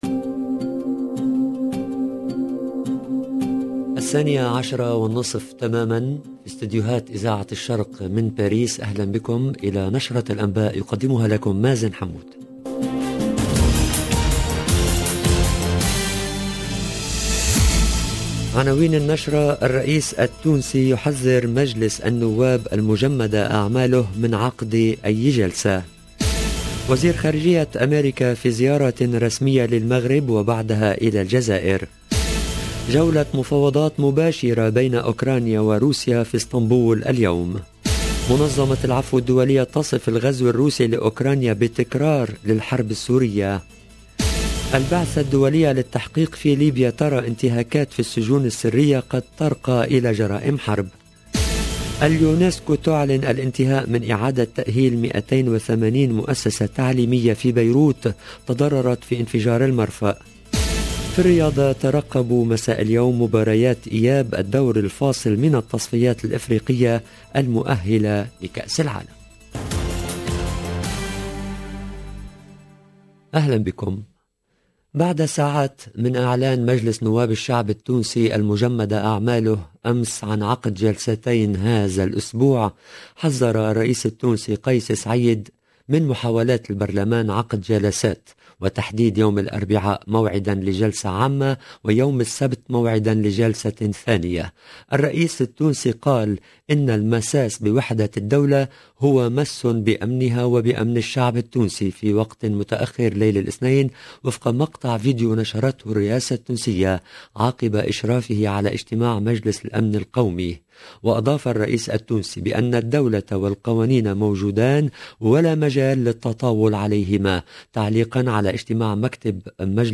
LE JOURNAL EN LANGUE ARABE DE MIDI 30 DU 29/03/22